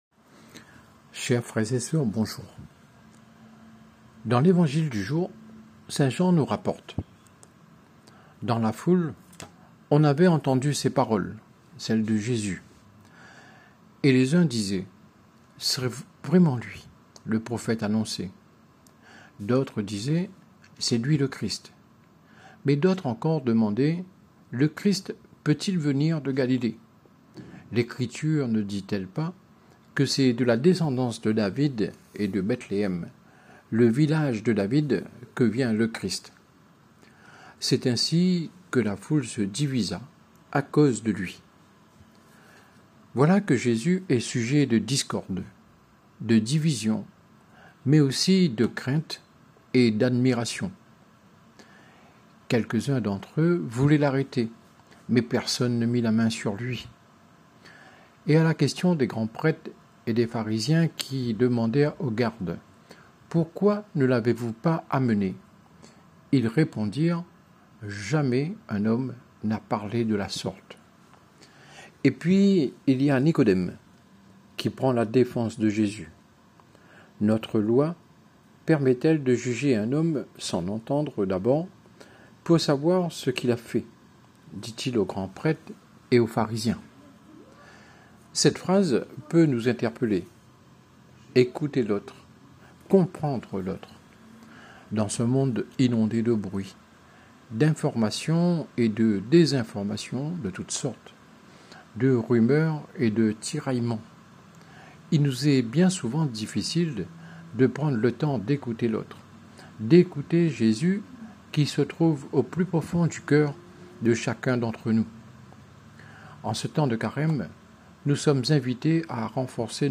Prédication disponible en format audio.